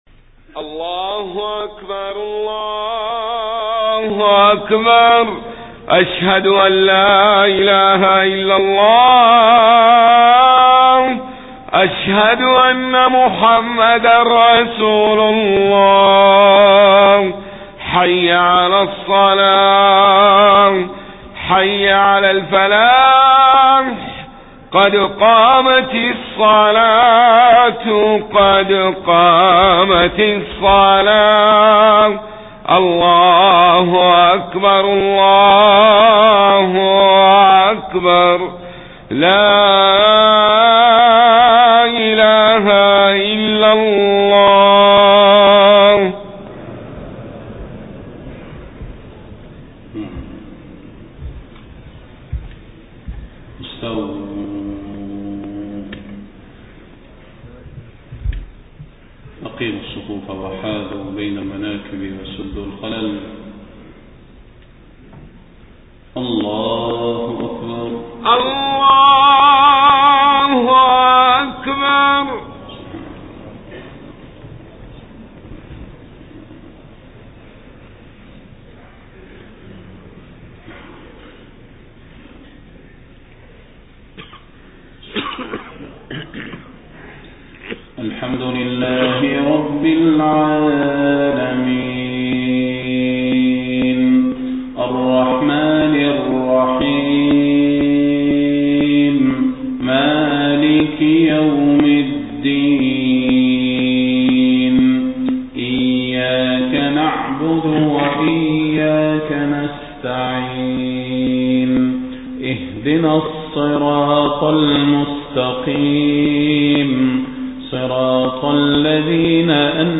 صلاة الفجر 23 صفر 1431هـ من سورة الأعراف 172-188 > 1431 🕌 > الفروض - تلاوات الحرمين